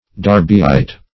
Darbyite \Dar"by*ite\, n.